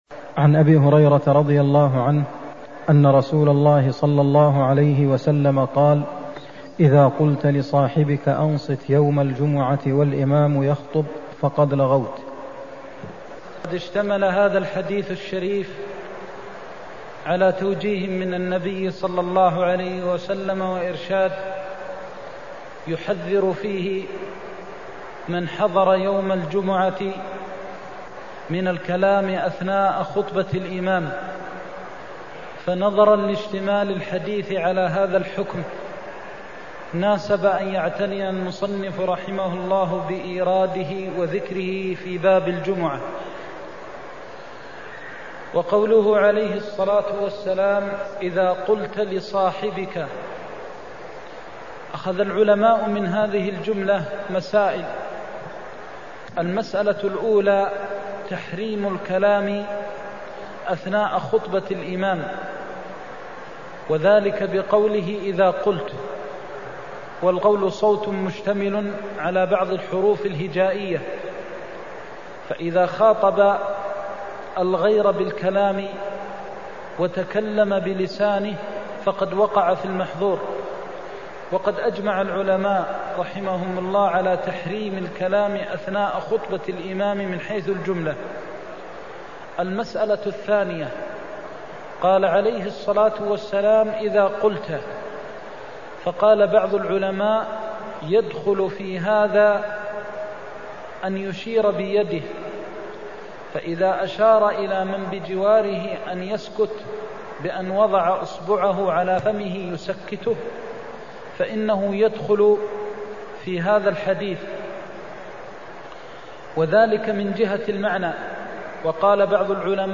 المكان: المسجد النبوي الشيخ: فضيلة الشيخ د. محمد بن محمد المختار فضيلة الشيخ د. محمد بن محمد المختار وجوب الإنصات يوم الجمعة (131)